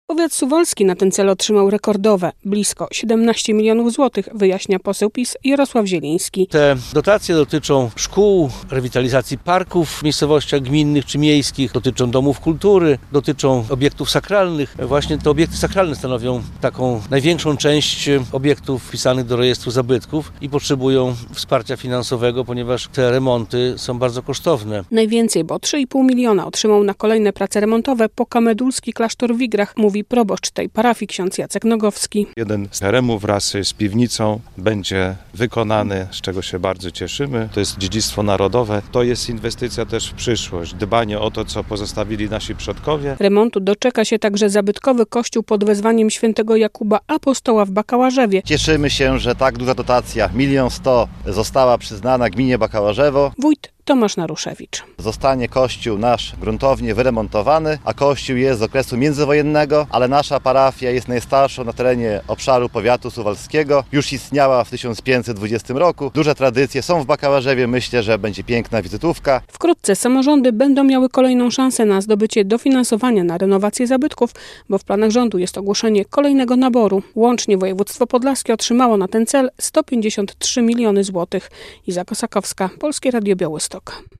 Powiat suwalski na ten cel otrzymał rekordowe blisko 17 mln zł - mówi poseł PiS Jarosław Zieliński.
Są duże tradycje w Bakałarzewie, myślę, że to będzie piękna wizytówka - podkreśla wójt Tomasz Naruszewicz.